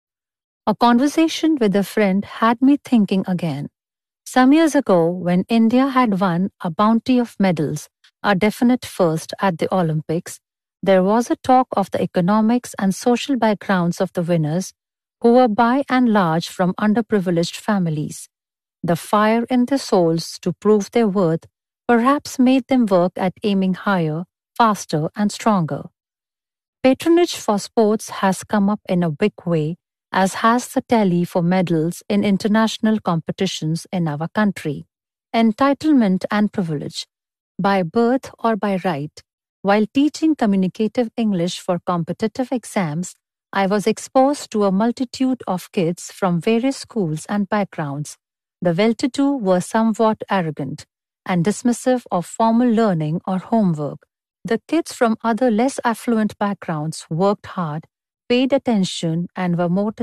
Authors Cafe an Audio Interview